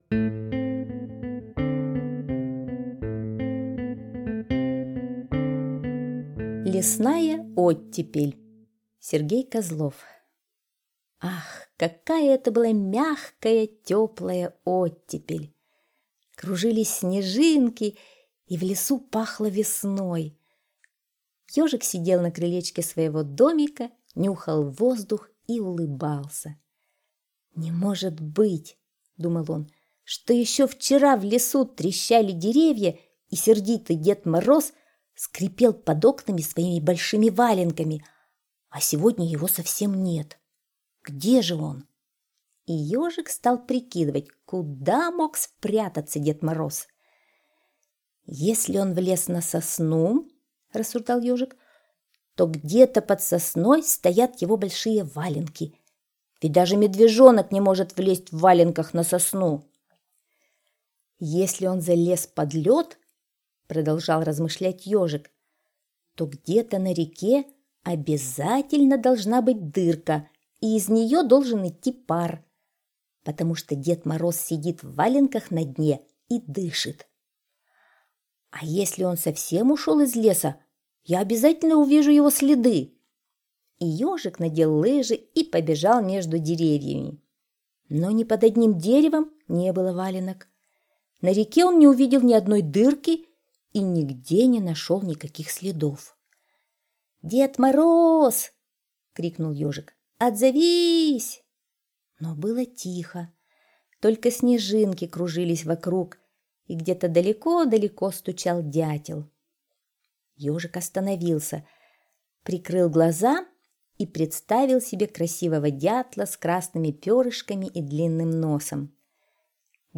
Слушайте Лесная оттепель - аудиосказка Козлова С.Г. Сказка про Ежика, который ранней весной в оттепель думал про Дед Мороза и искал его следы.